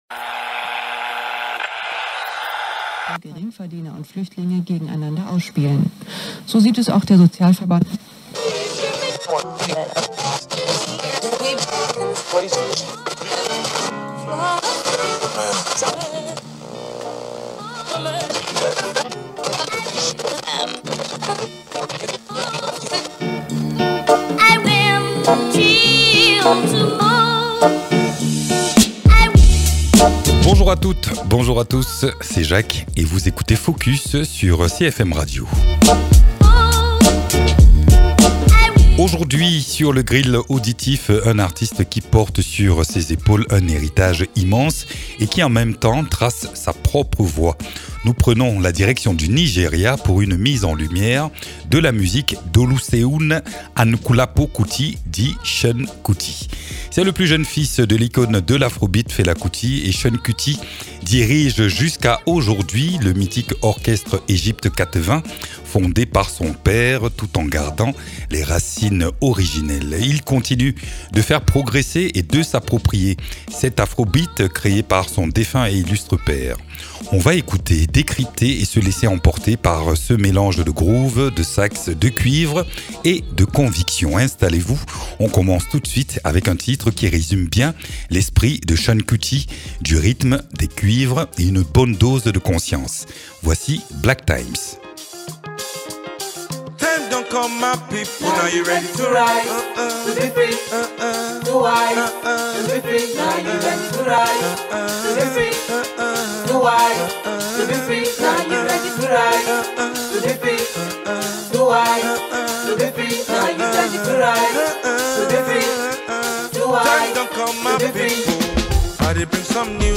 Saxophoniste, chanteur et agitateur d’idées